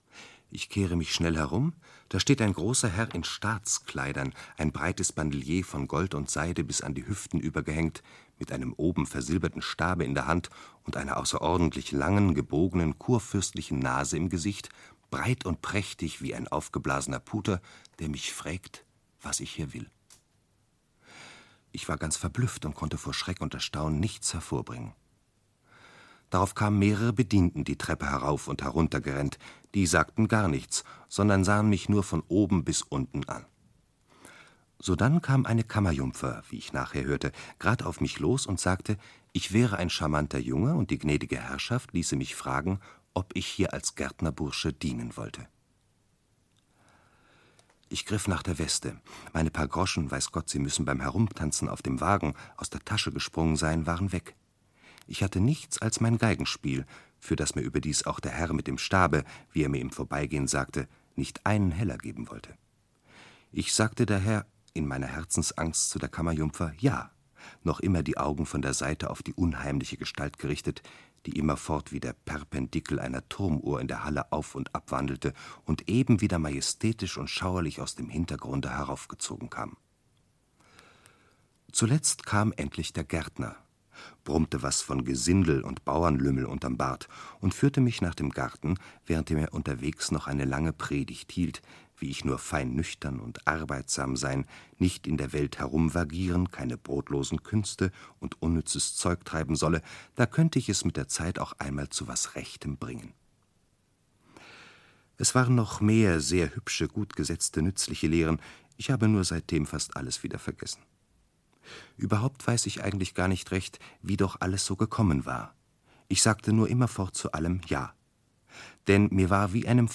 Aus dem Leben eines Taugenichts - Joseph von Eichendorff - Hörbuch